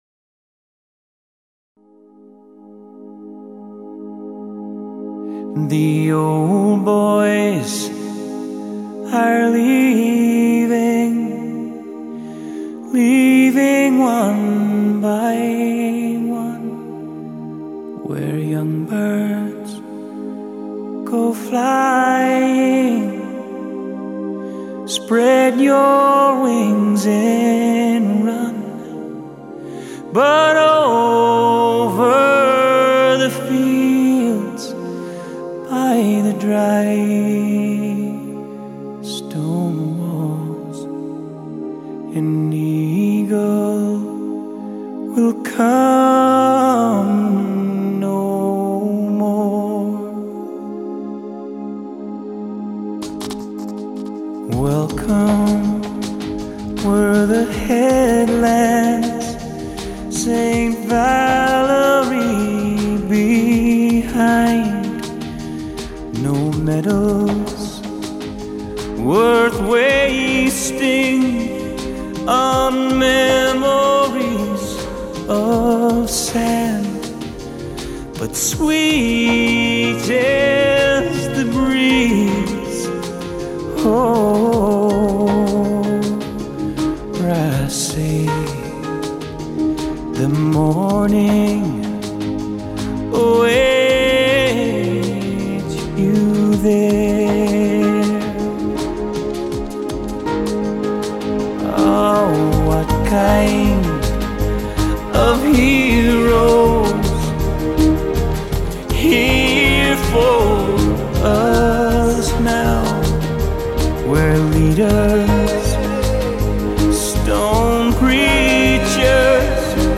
Style: Folk-Rock